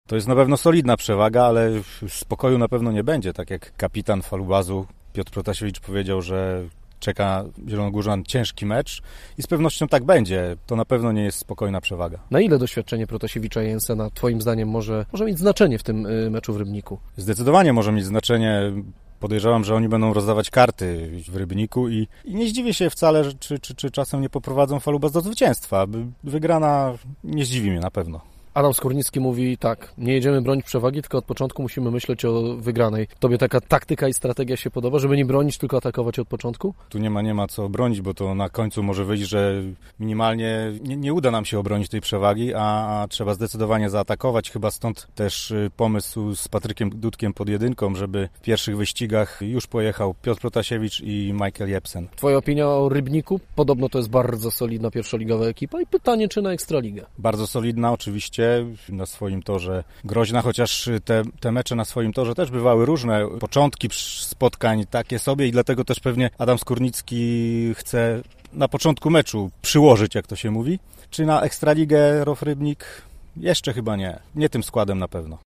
O tym rozmawiamy z naszym ekspertem